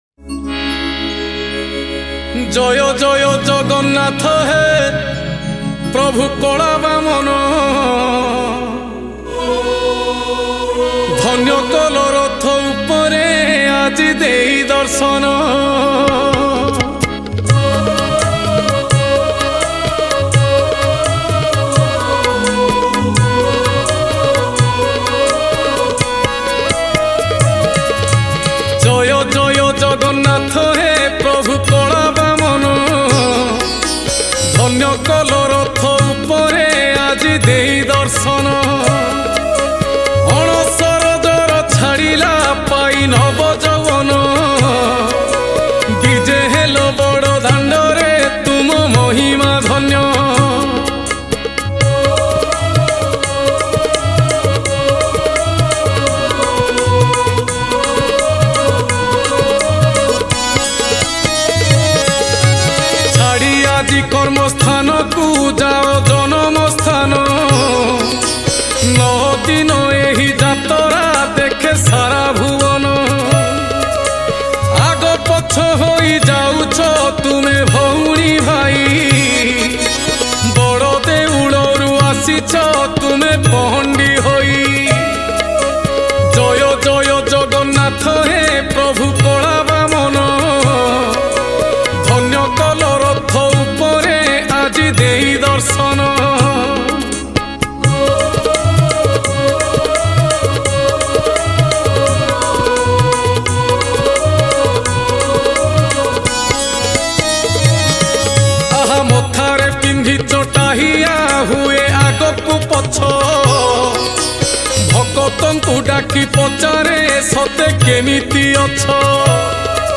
Category : Ratha Yatra Odia Bhajan 2023